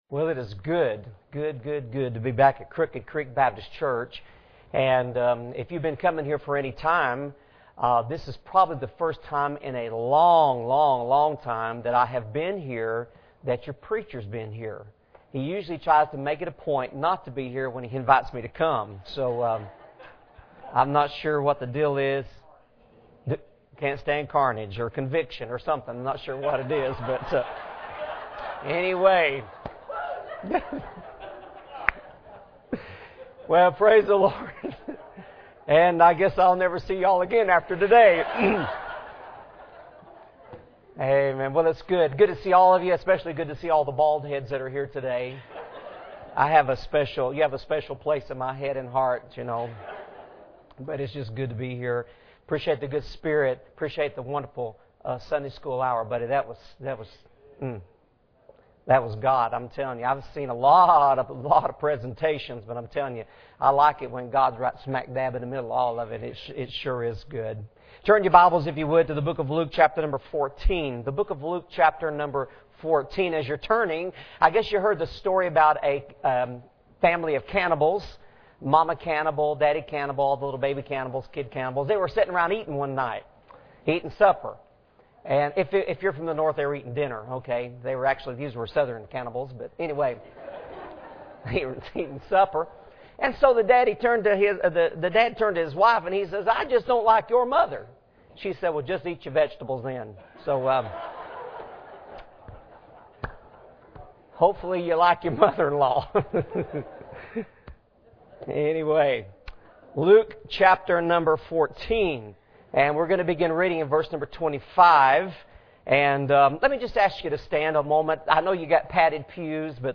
Passage: Luke 14:16-35 Service Type: Sunday Morning